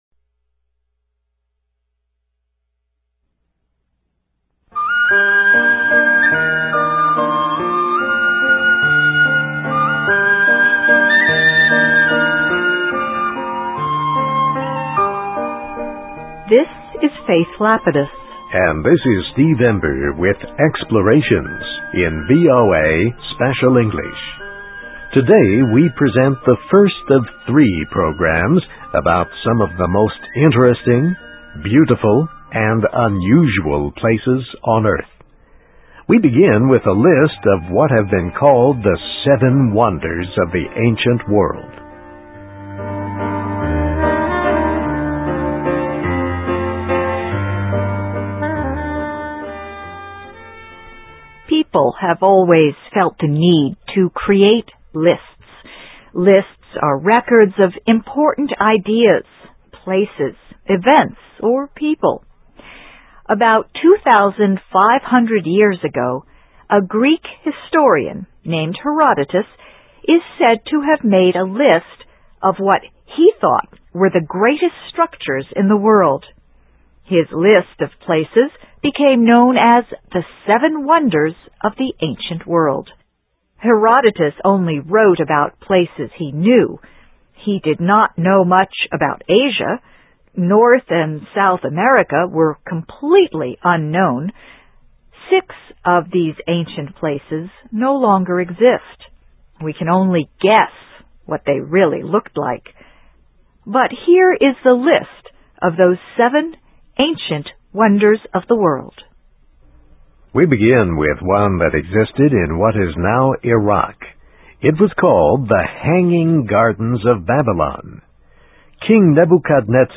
Places: Seven Wonders of the Ancient World, Plus a Few (VOA Special English 2008-01-01)
Listen and Read Along - Text with Audio - For ESL Students - For Learning English